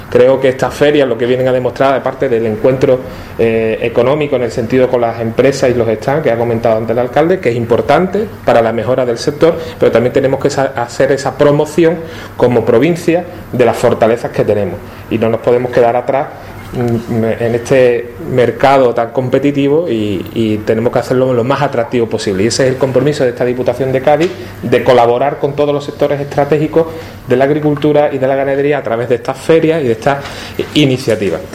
El diputado provincial responsable del Área de Desarrollo Económico y Servicios a la Ciudadanía, Jaime Armario, ha querido destacar la colaboración que desde la Diputación y la Institución Ferial Ifeca se lleva a cabo con las diferentes ferias y muestras que se celebran en la provincia de Cádiz.
Presentación de Olivera 2016
Jaime Armario sobre Olivera